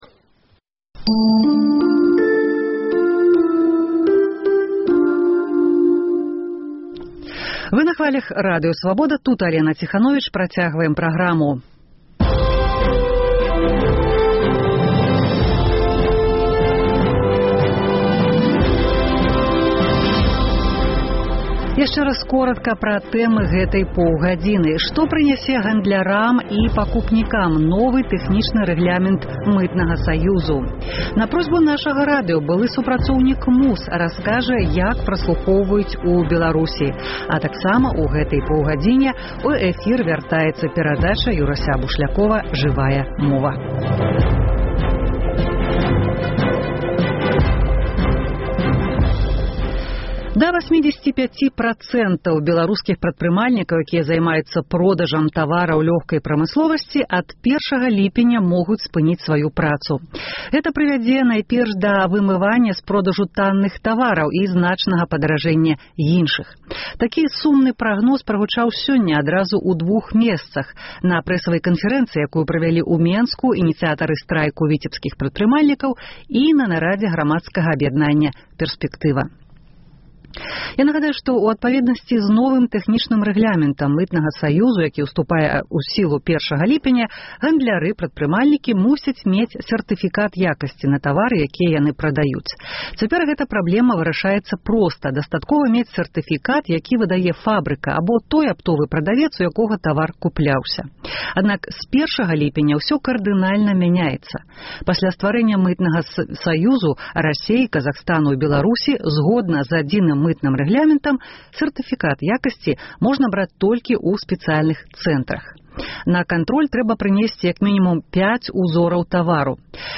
Рэпартаж з вёскі Мардзьвін Петрыкаўскага раёну. Беларускія аспэкты амэрыканскага скандалу з сачэньнем і праслухоўваньнем. Дзе і як ў Беларусі можна зрабіць вакцынацыю супраць раку жаночых органаў?